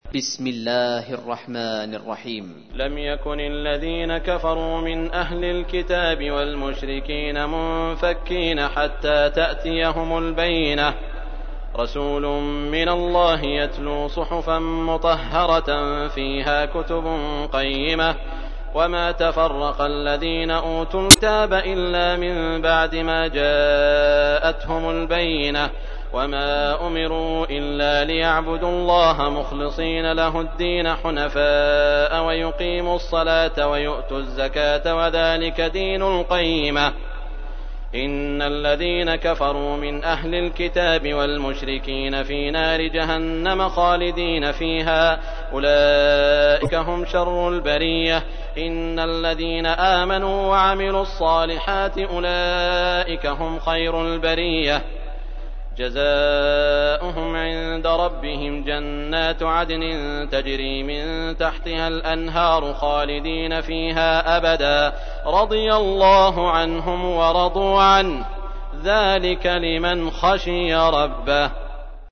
تحميل : 98. سورة البينة / القارئ سعود الشريم / القرآن الكريم / موقع يا حسين